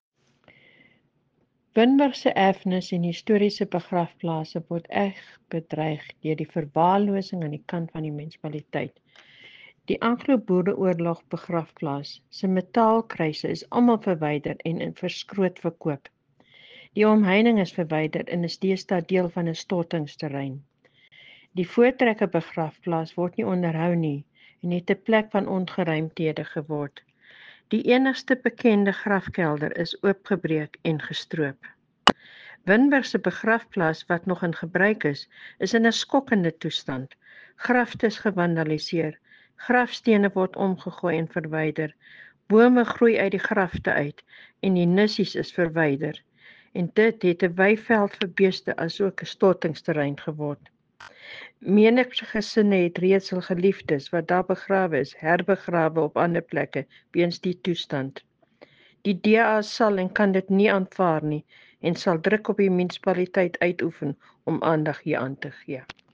Afrikaans soundbites by Cllr Brunhilde Rossouw and